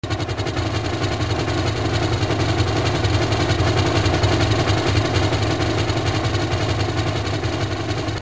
Comproveu que fa l'efecte que l'helicòpter es mou d'esquerra a dreta.